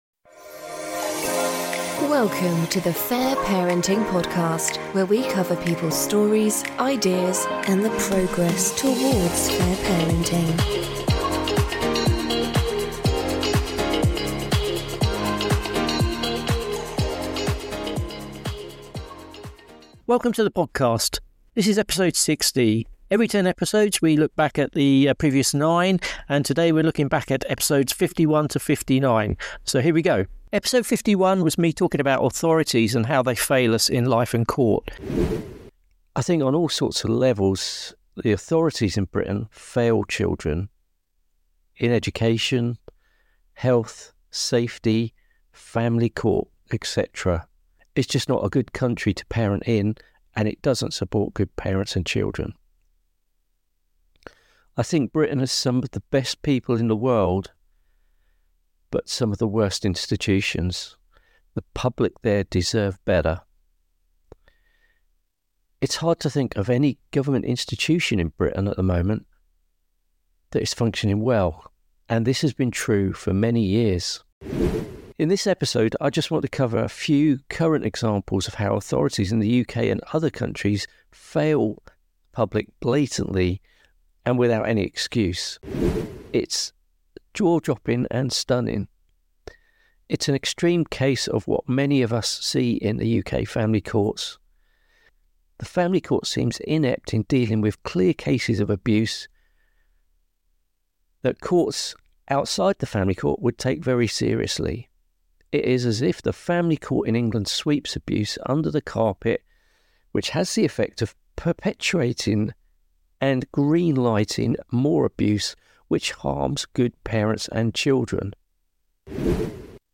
This episode is a compilation of clips but is not exactly a ‘best of’ as that would differ from listener to listener.